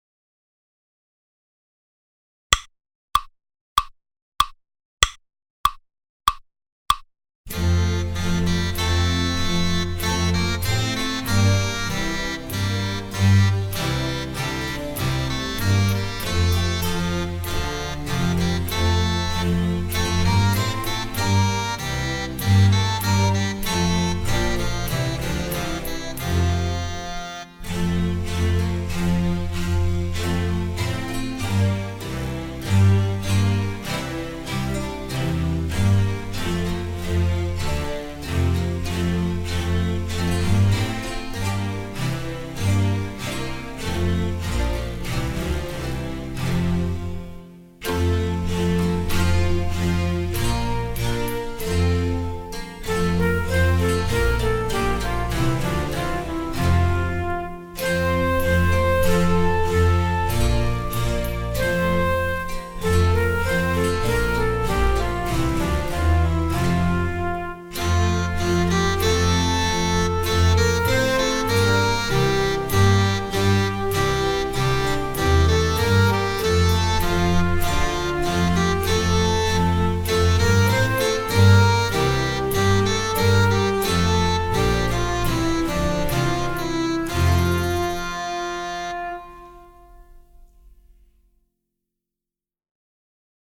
Proposta D: Audio velocidade lenta sen frauta
Susato_LENTO_SEN_FRAUTA.mp3